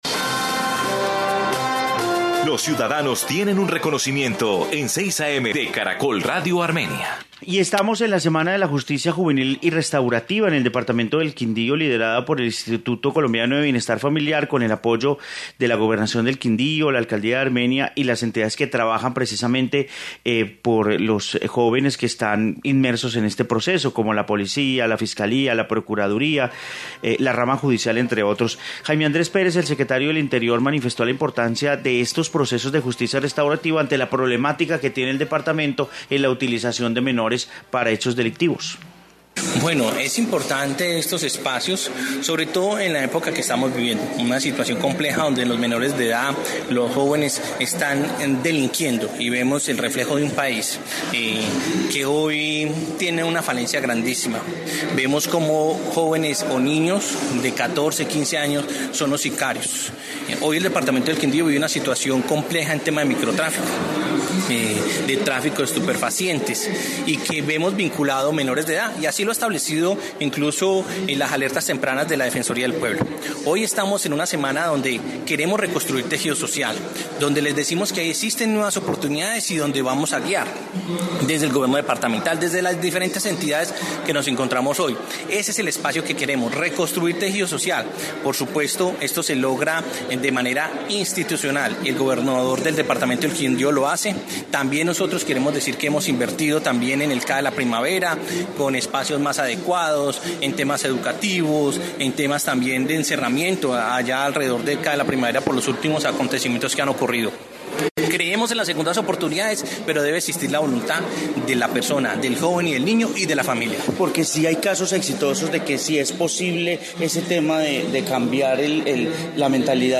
Informe sobre justicia restaurativa en Quindío